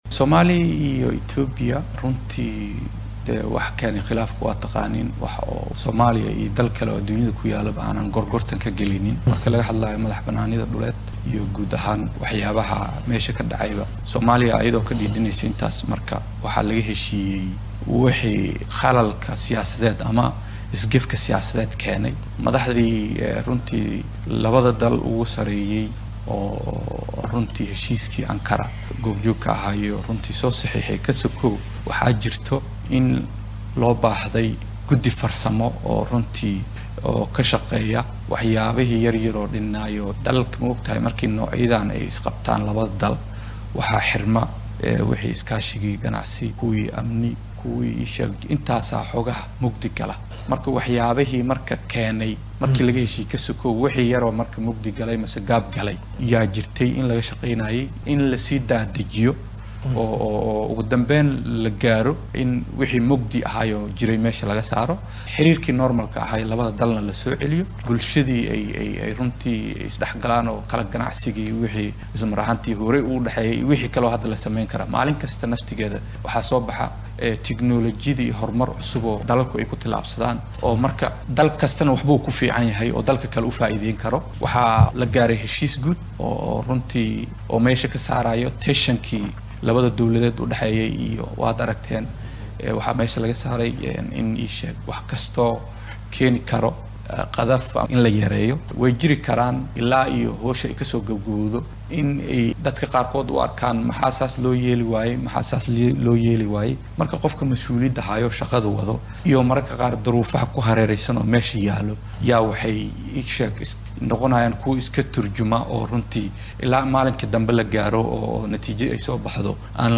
Wasiir ku xigeenka wasaaradda arrimaha dibadda ee xukuumadda federaalka Soomaaliya Isaaq Mursal oo u warramay telefishinka qaranka soomaaliyeed ayaa sheegay inuu soo hagaagaya xiriirka dibloomaasiyadeed ee ka dhexeeya dalalka dariska ah ee Soomaaliya iyo Itoobiya iyadoo ay socdaan wada hadallo looga heshiinaya qaladaad horay u dhacay.